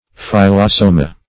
Phyllosoma \Phyl`lo*so"ma\ (f[i^]l`l[-o]*s[=o]"m[.a]), n. [NL.